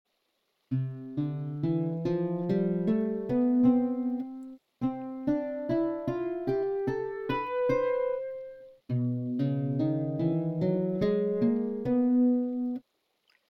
Basic_Harp_Sound.mp3